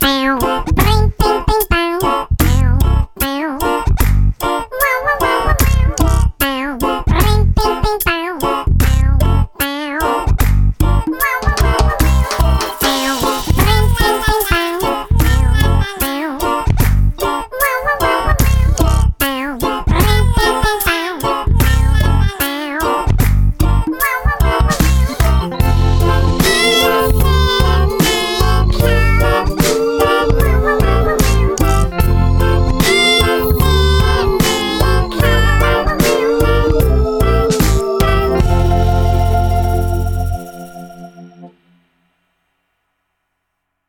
• Качество: 320, Stereo
милые
веселые
забавный голос